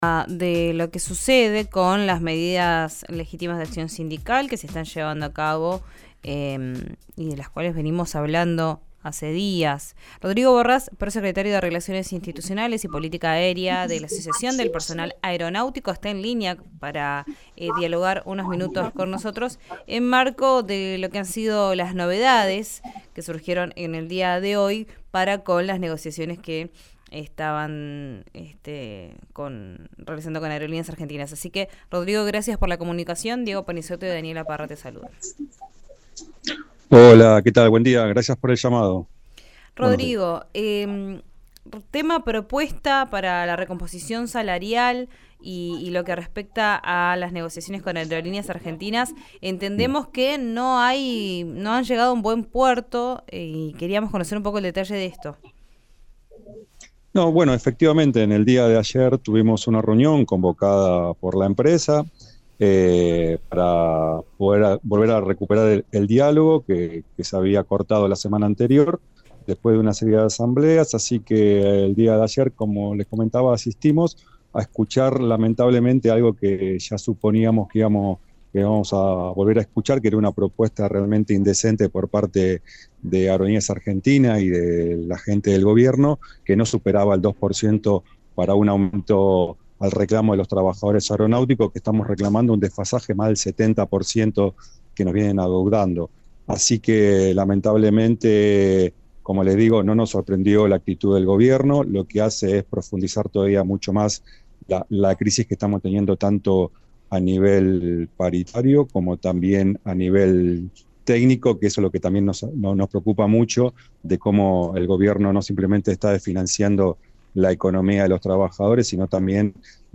en diálogo con RN Radio